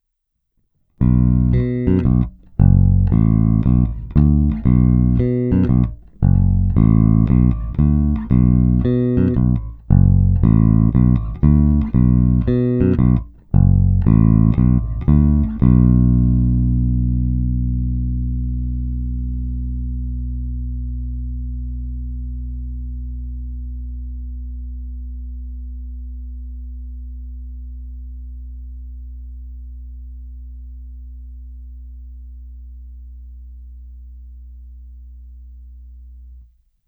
Není-li uvedeno jinak, následující nahrávky jsou provedeny rovnou do zvukové karty a s plně otevřenou tónovou clonou.
Hráno nad použitým snímačem, v případě obou hráno mezi nimi.
Oba snímače